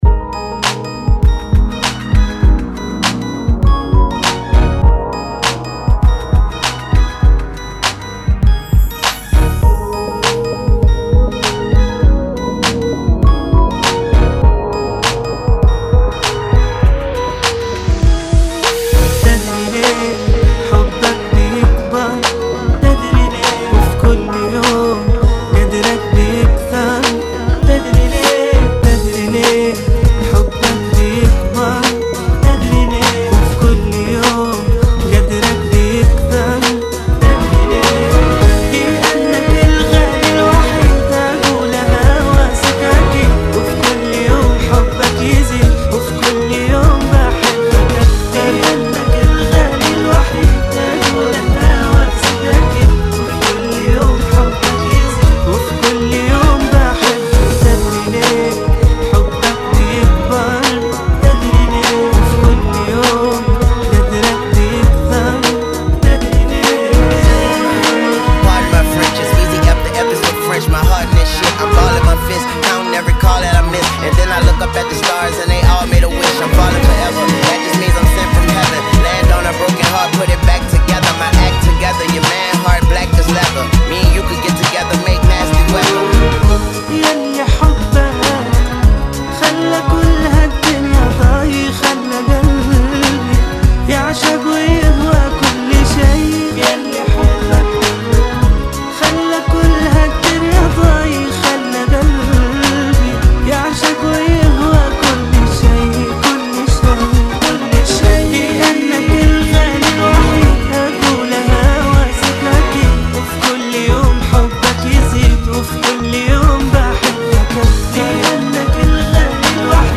[ 100 Bpm ]